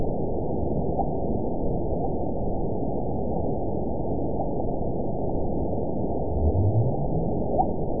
event 912019 date 03/15/22 time 20:43:41 GMT (3 years, 1 month ago) score 9.55 location TSS-AB08 detected by nrw target species NRW annotations +NRW Spectrogram: Frequency (kHz) vs. Time (s) audio not available .wav